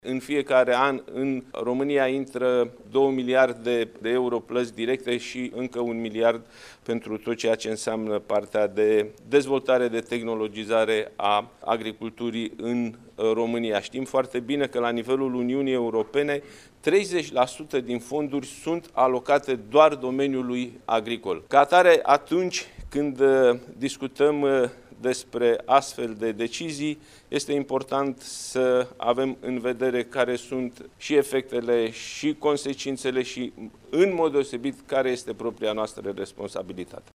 Președintele PNL, Nicolae Ciucă, președinte al Senatului, a susținut, astăzi, la Hotelul Internațional din Iași, o conferință de presă în care a afirmat că înainte de a solicita demisia comisarului european pentru Agricultură, așa cum susține ministrul de restort, Florin Barbu, ar trebui să ne gândim la efectele și consecințele acestei solicitări pentru țara noastră.